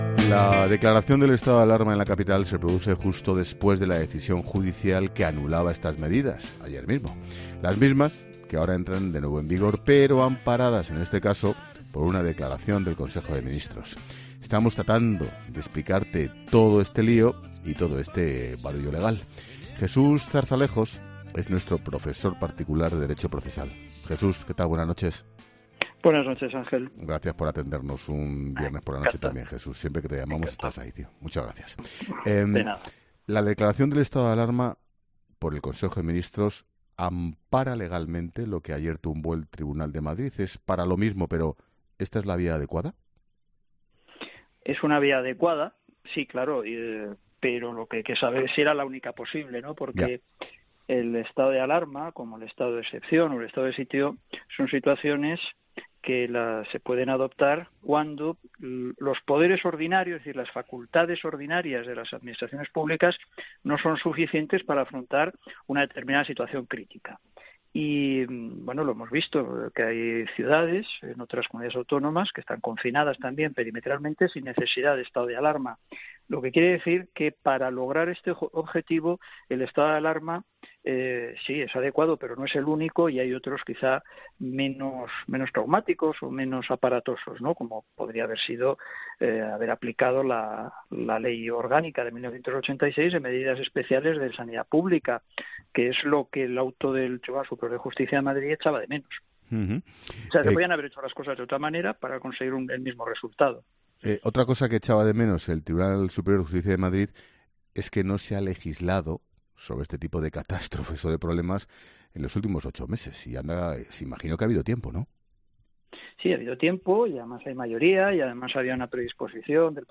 La Linterna es la suma de la información, el análisis y la opinión.